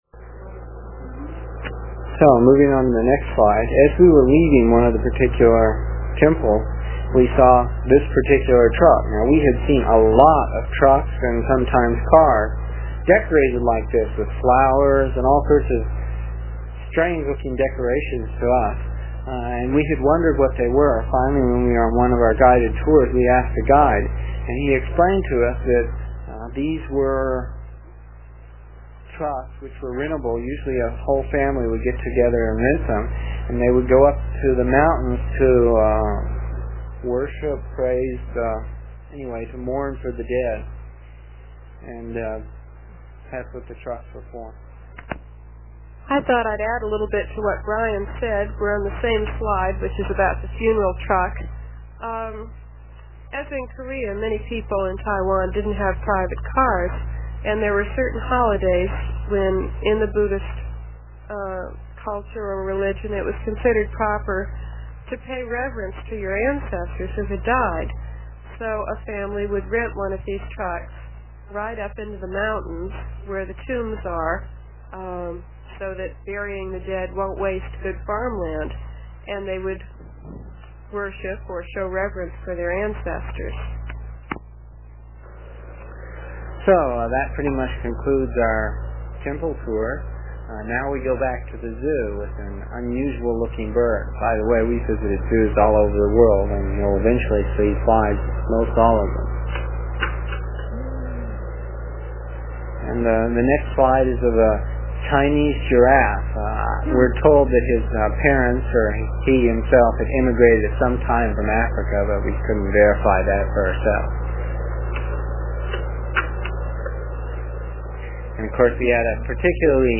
It is from the cassette tapes we made almost thirty years ago. I was pretty long winded (no rehearsals or editting and tapes were cheap) and the section for this page is about seven minutes and will take about three minutes to download with a dial up connection.